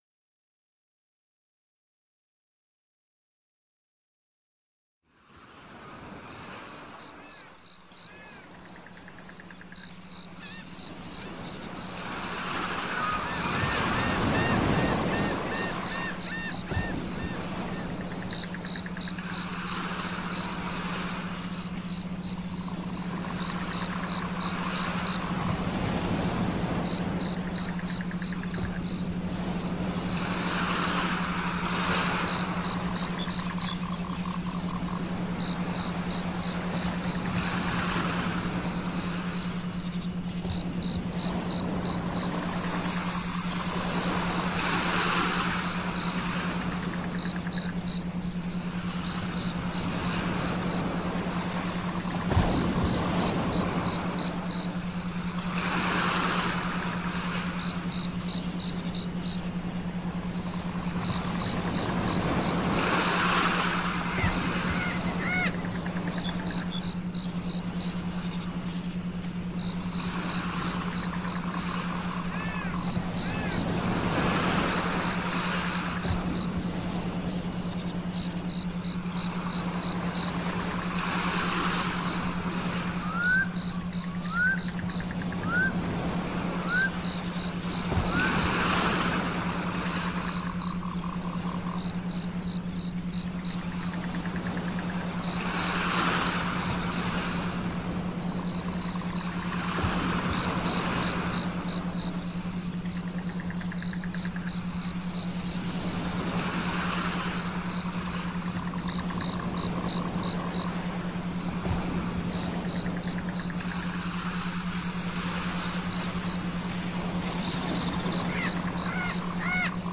Superlearning Hintergurnd Musik
superlearning-172Hz.mp3